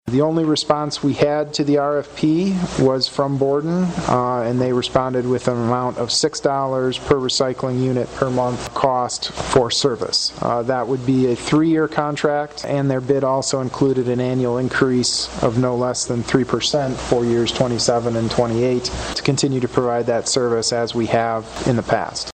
Wednesday night, City Manager Andrew Kuk shared with the City Commission information on the curbside recycling program, starting with how it began.